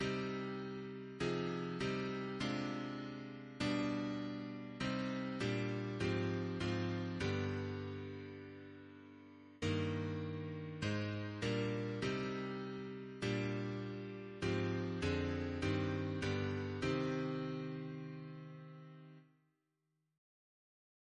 Double chant in C minor Composer: Chris Biemesderfer (b.1958)